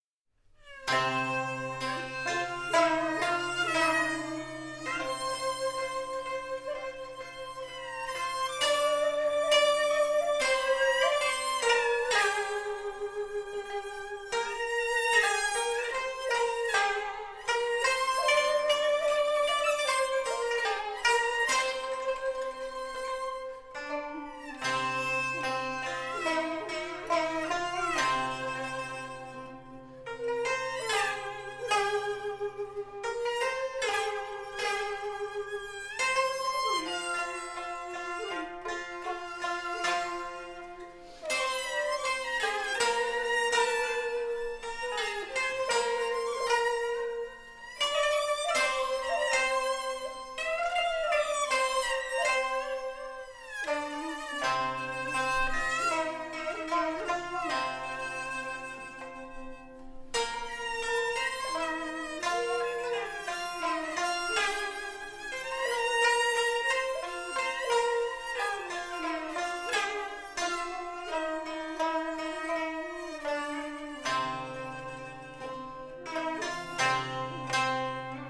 专辑风格：民乐、广东音乐
整张唱片呈现出的音色爽朗、清晰、通透，乐器的定位准、质感好